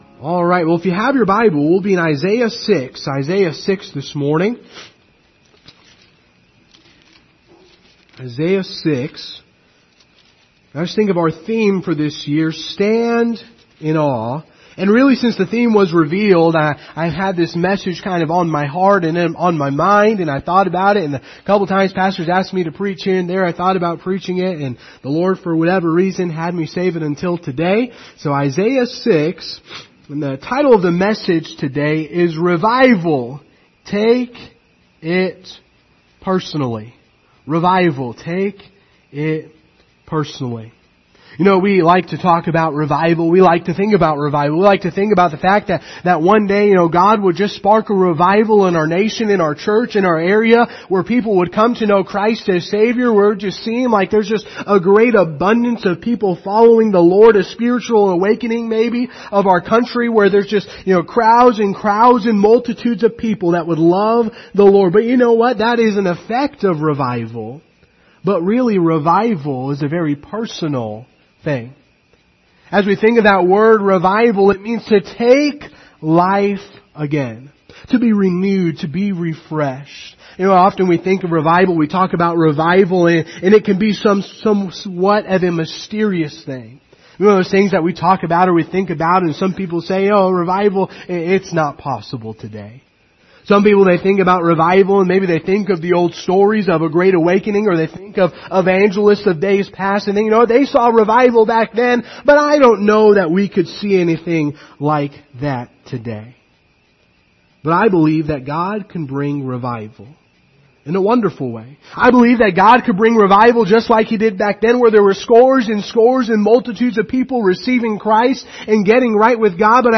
Service Type: Sunday Morning Topics: holiness , revival